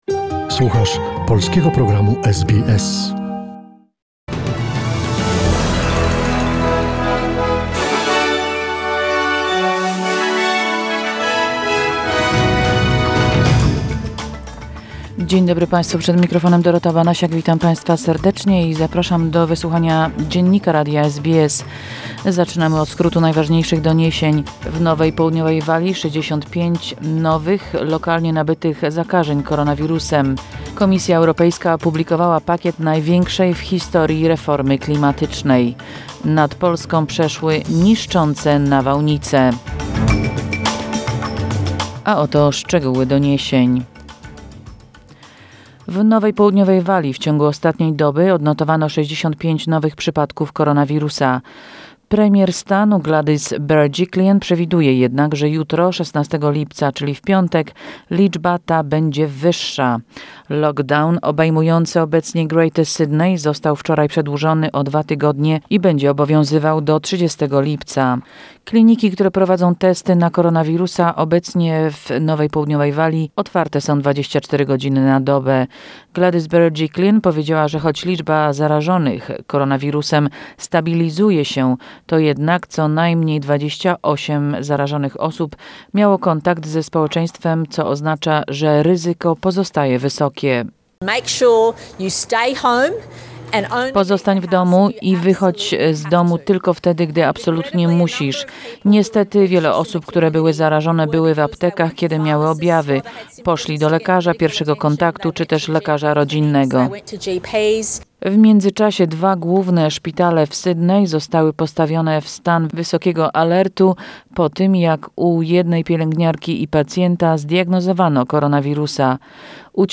SBS News Flash in Polish, 15 July 2021